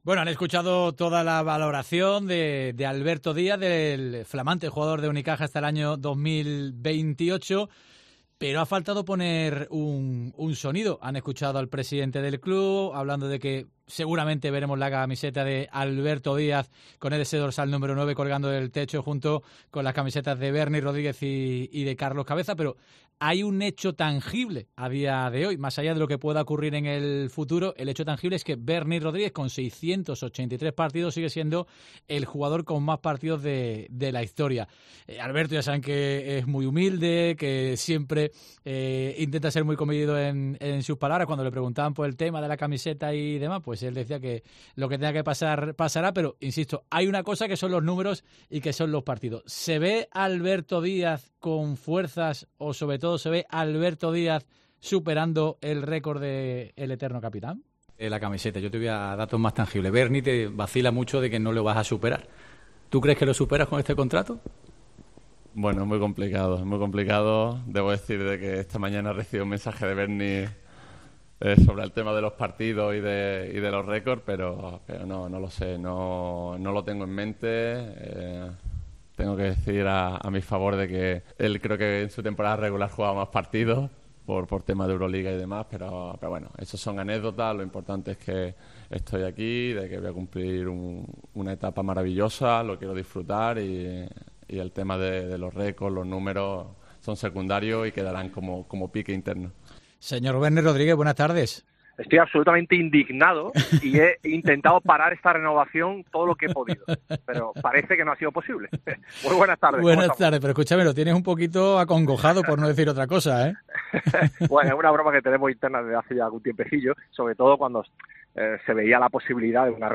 El "eterno" capitán de Unicaja habló en COPE de la renovación de Alberto Díaz que amenaza su récord de ser el jugador con más partidos en la historia de Unicaja